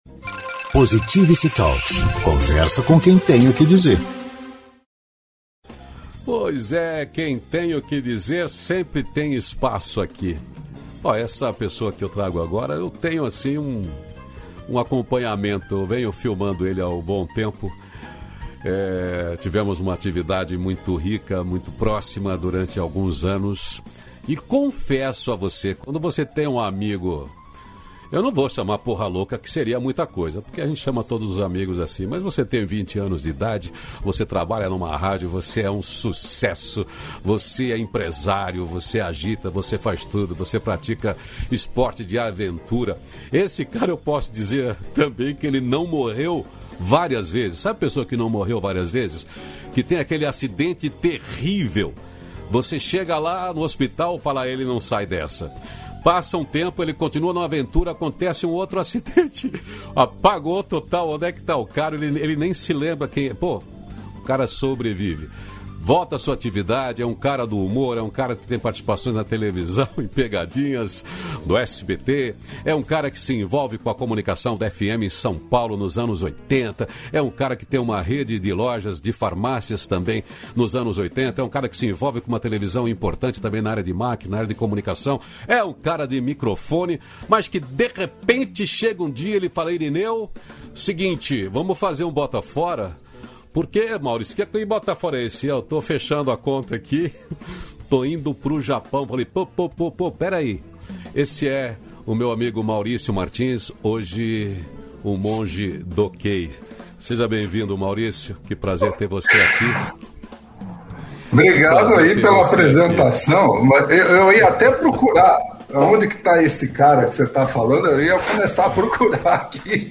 241-feliz-dia-novo-entrevista.mp3